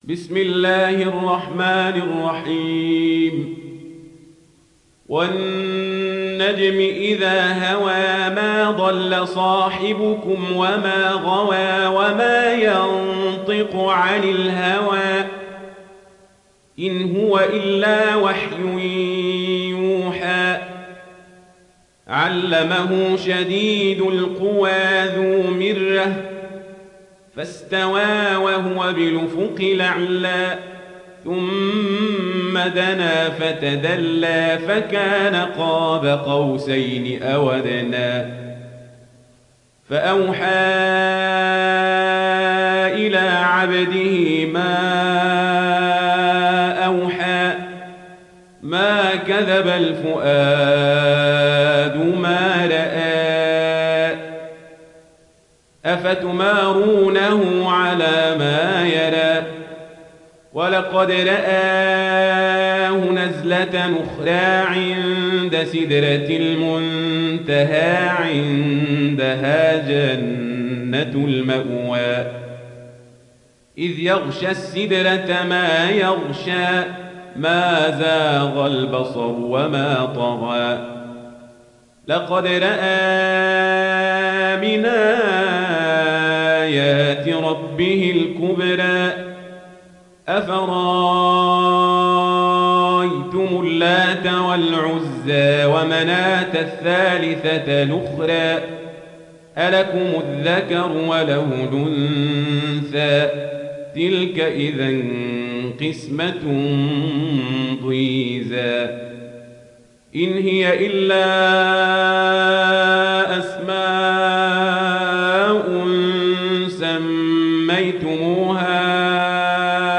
تحميل سورة النجم mp3 بصوت عمر القزابري برواية ورش عن نافع, تحميل استماع القرآن الكريم على الجوال mp3 كاملا بروابط مباشرة وسريعة